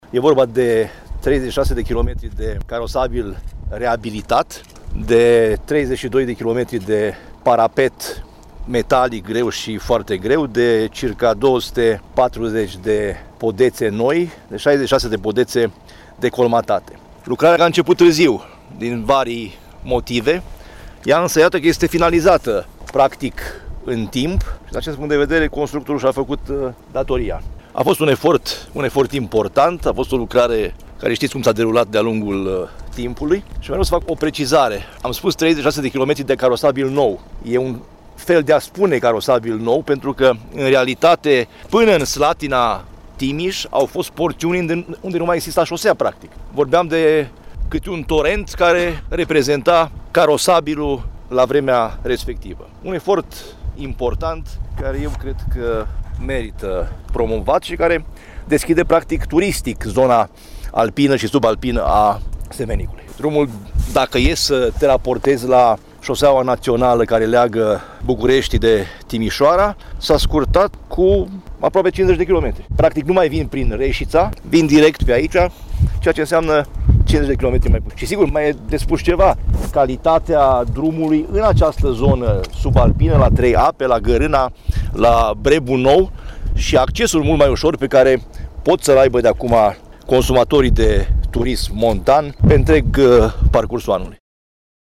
Drumul Judeţean 582 care leagă localităţile Văliug cu Slatina Timiş a fost finalizat. Invetiţia a fost de aproape 33 de milioane de euro, bani din fonduri europene. Despre aceasă lucrare, prseşdinte Consiliului Judeţean Caraş-Severin, Sorin Frunzăverde a afirmat: